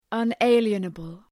Προφορά
{ʌn’eıljənəbəl}